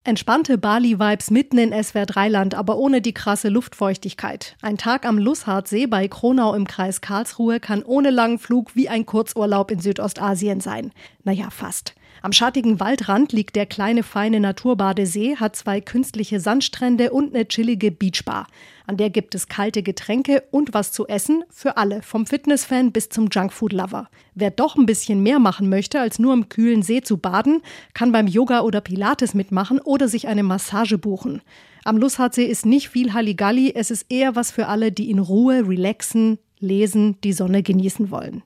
Nachrichten „Ein kleiner, feiner Naturbadesee“